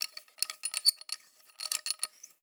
SFX_Metal Sounds_01.wav